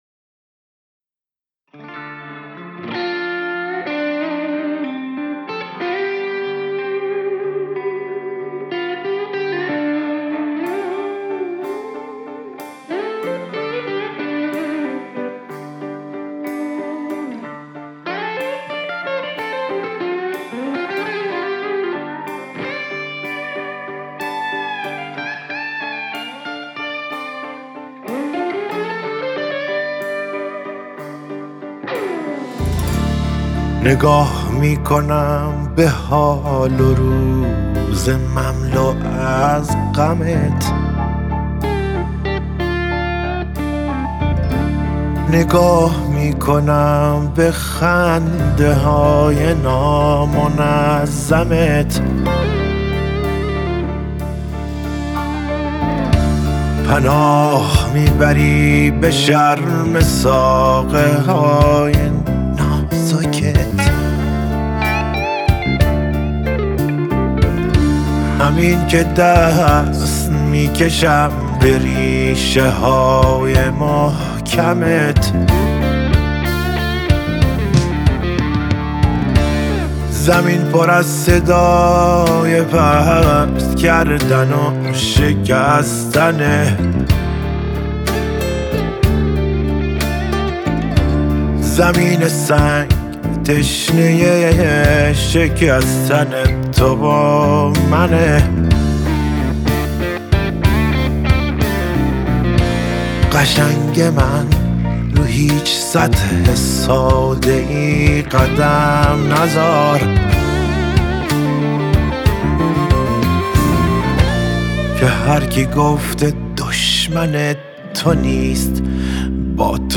• دسته آهنگ پاپ